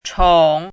怎么读
chǒng
chong3.mp3